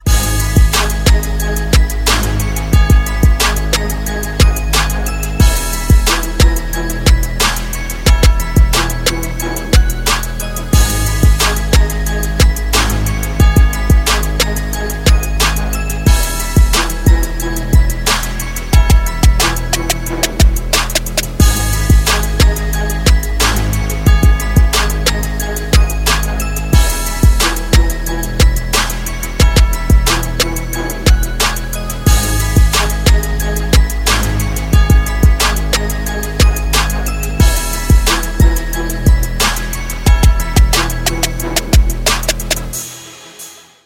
Мелодии на звонок
Нарезка на смс или будильник